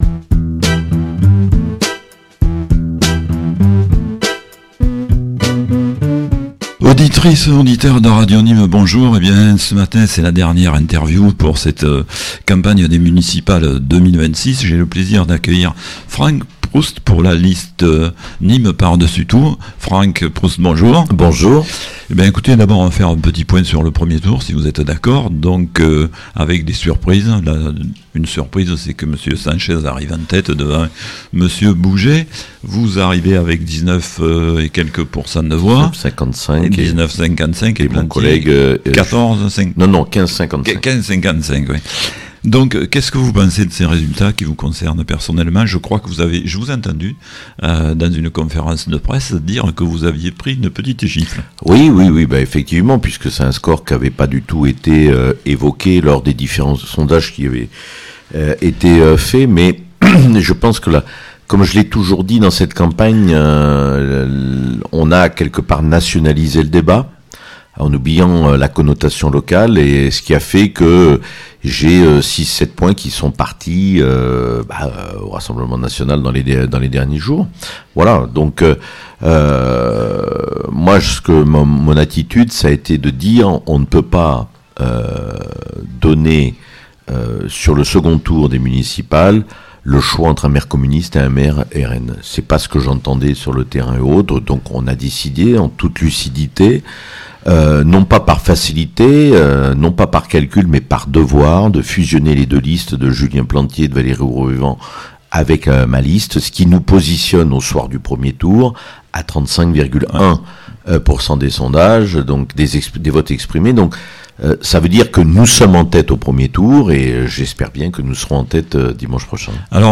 Municipales 2026 - Entretien d'entre-deux-tours avec Franck PROUST (Nîmes par-dessus tout) - EMISSION DU 20 MARS